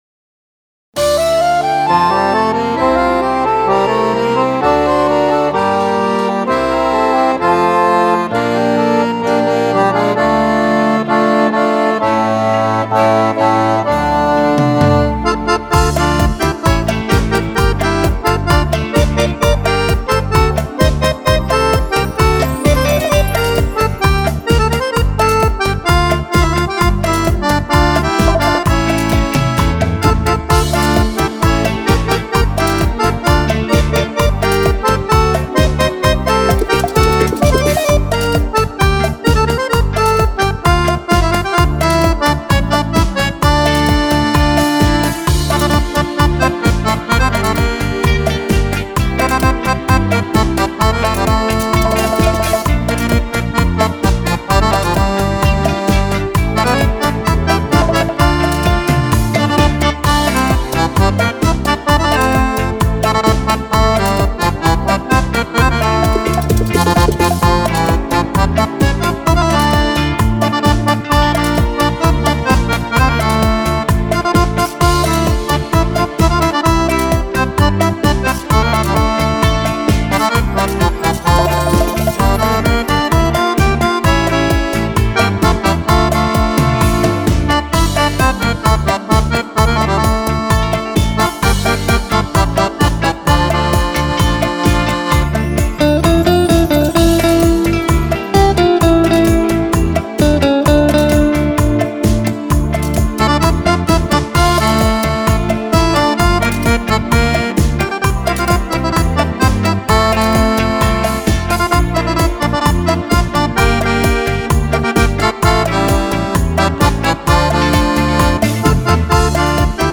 Bachata
10 BALLABILI PER FISARMONICA